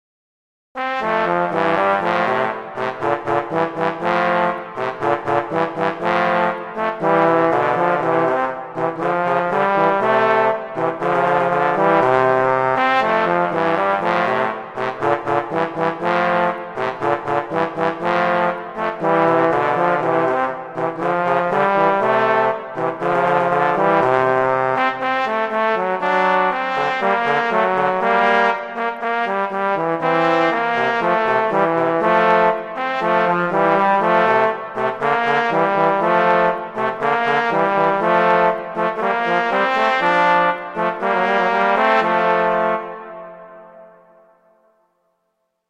transcription for two trombones
classical